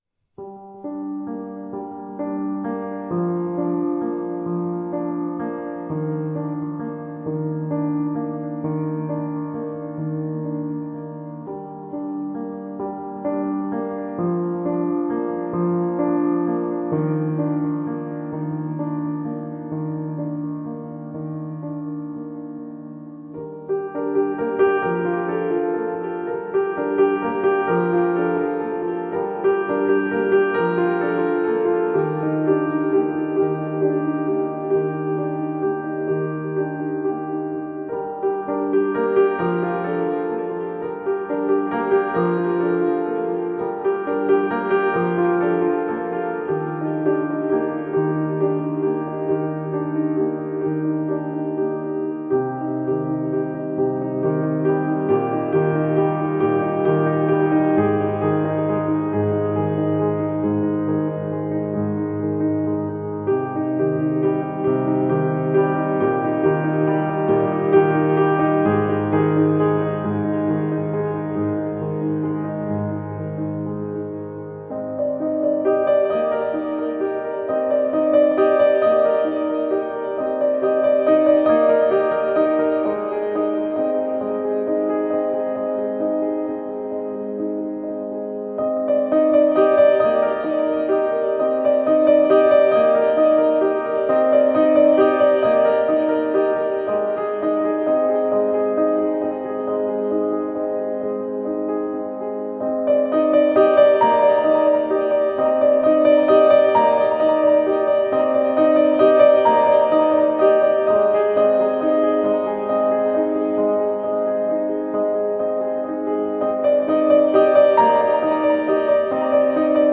Neo Classical/Ambient piano composition